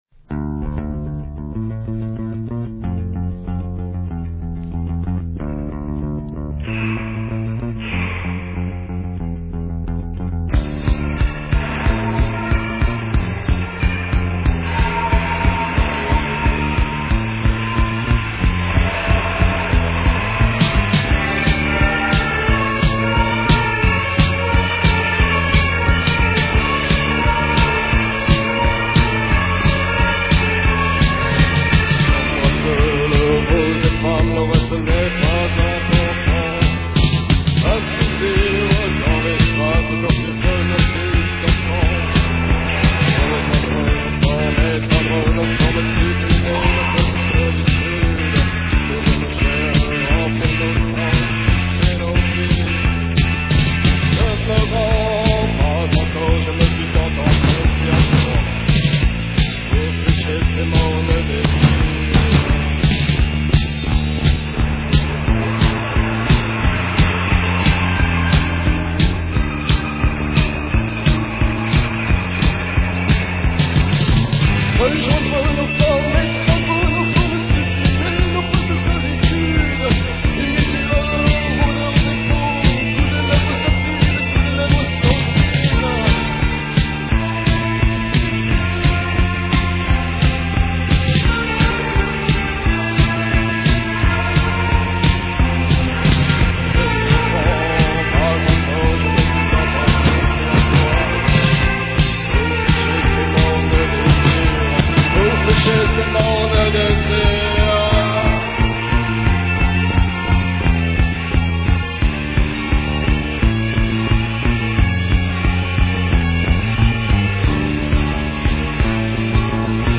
Cold wave Premier 45t retour à l'accueil